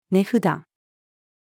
値札-female.mp3